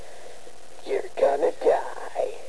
Can you give me your best Vegita?